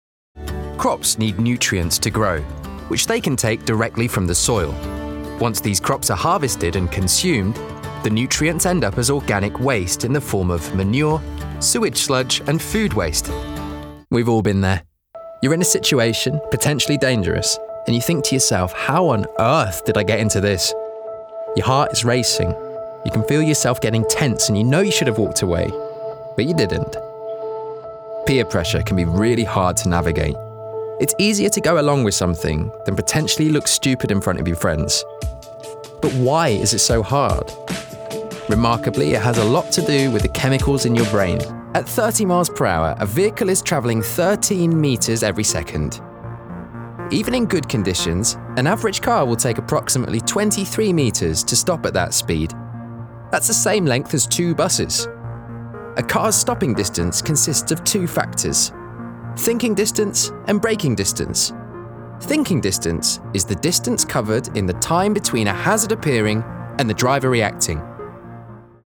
Engels (Brits)
Commercieel, Natuurlijk, Stoer, Toegankelijk, Vriendelijk
E-learning